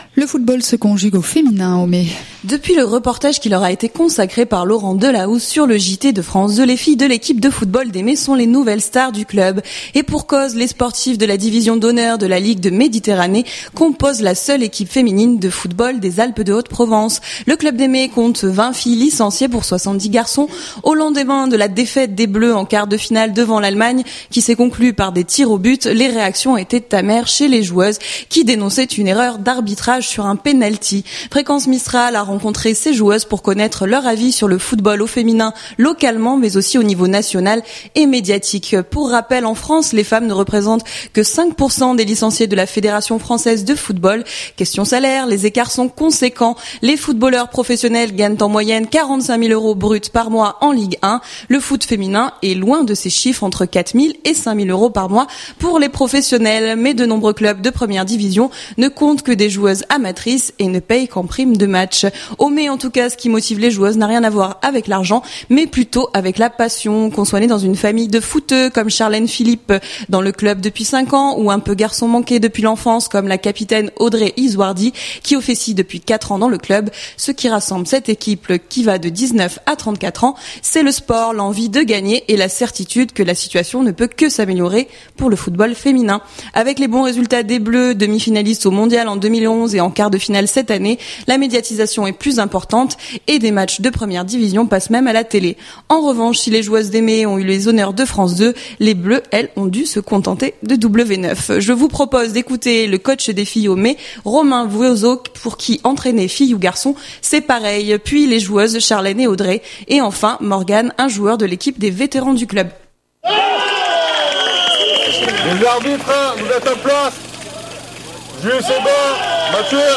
Fréquence Mistral les a rencontrées lors d'une séance de matchs un samedi matin pour connaître leur avis sur le football au féminin au niveau local mais aussi national et sur la couverture médiatique.